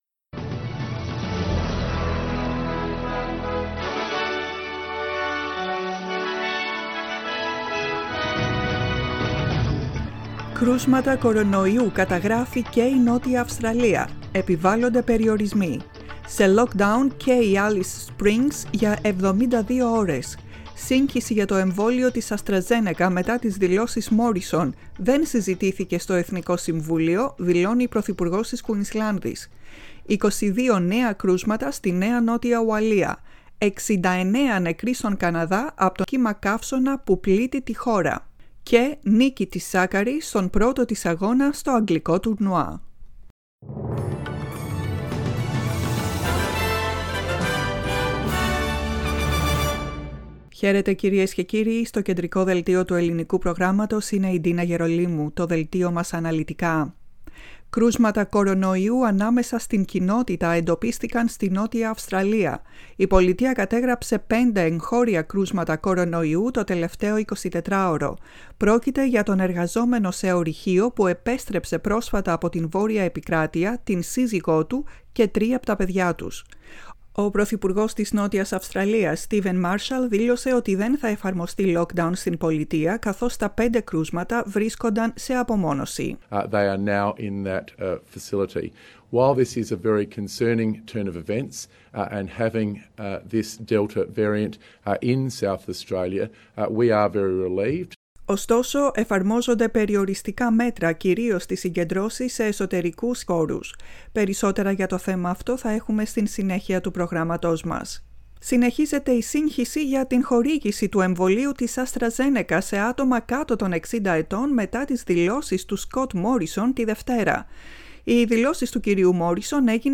Δελτίο ειδήσεων, 30.06.21
Ακούστε το κεντρικό δελτίο ειδήσεων του ελληνικού προγράμματος.